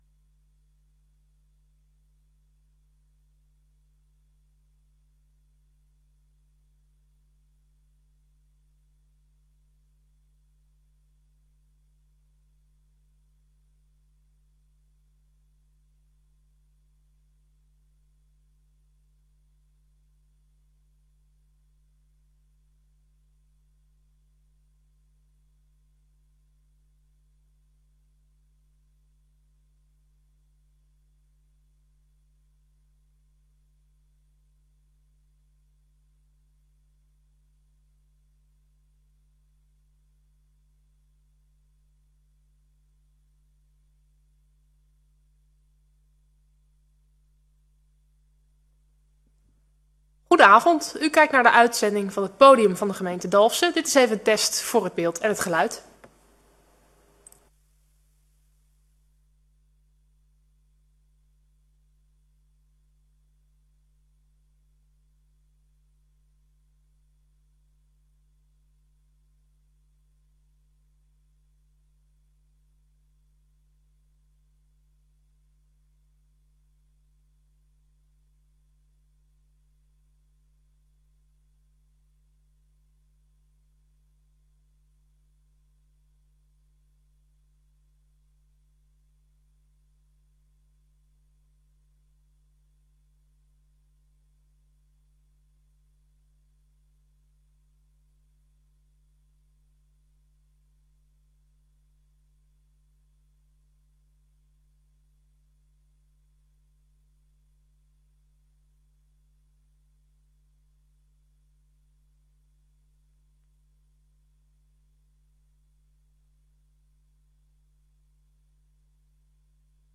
Gemeenteraadsleden mogen in deze tijd ook vragen aan u stellen.
Het Podium wordt gehouden in de raadzaal van het gemeentehuis in Dalfsen, maar u mag het gesprek ook voeren via MS Teams.